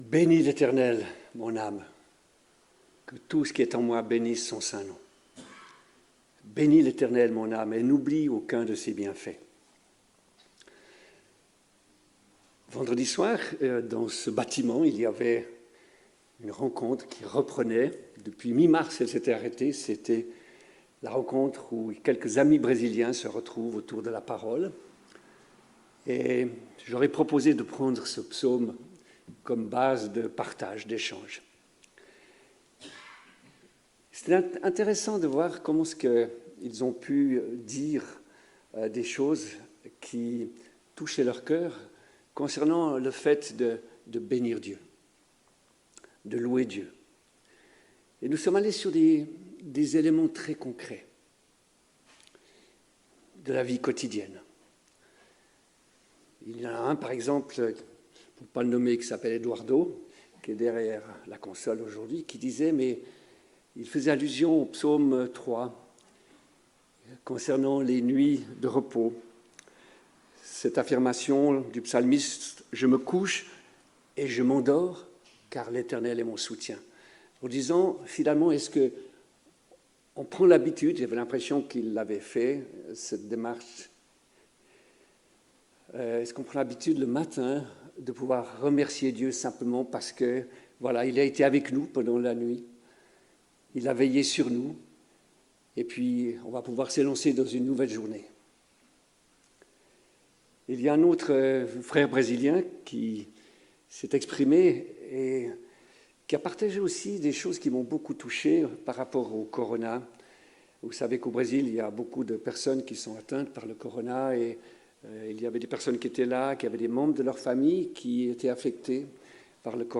Culte du 19 juillet 2020 Psaume 103